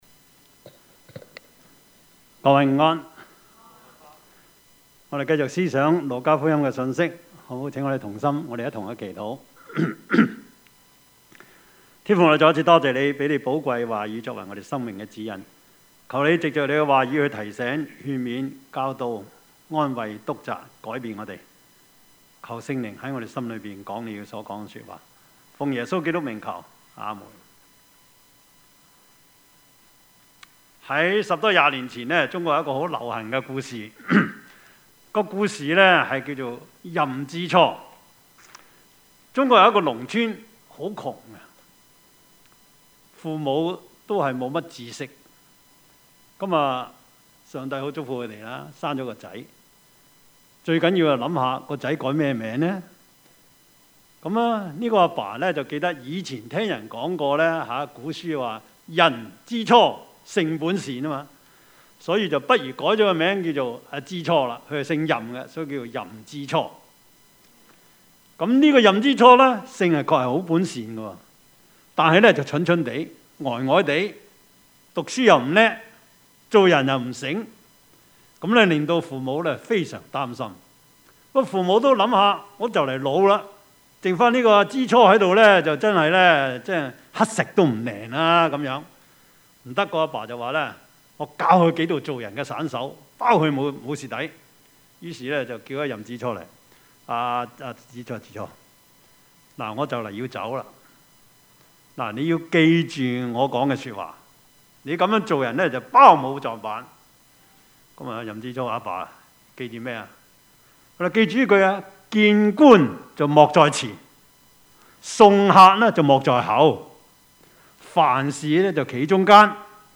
Service Type: 主日崇拜
Topics: 主日證道 « 神喜悅的祭 神必有預備 »